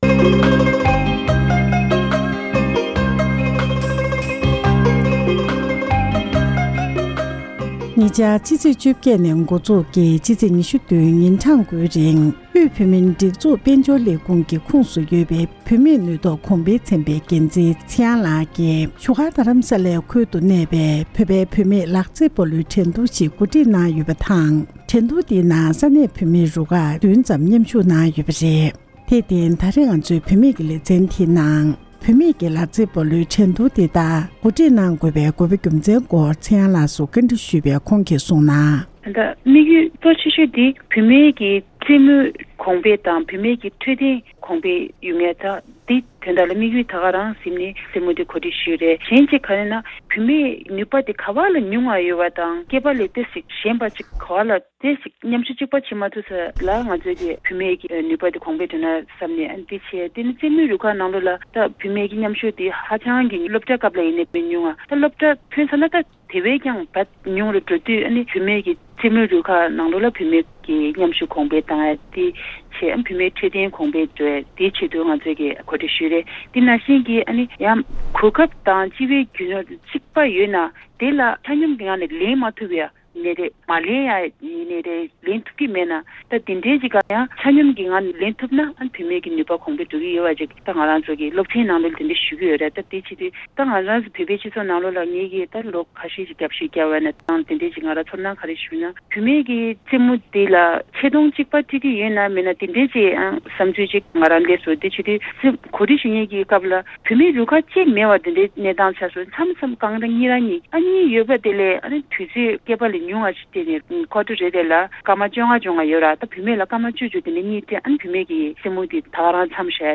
འབྲེལ་ཡོད་མི་སྣར་ཞལ་པར་བརྒྱུད་ཐད་ཀར་གནས་འདྲི་ཞུས་པ་ཞིག་གསན་རོགས་གནང་།།